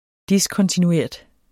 Udtale [ ˈdiskʌntinuˌeɐ̯ˀd ]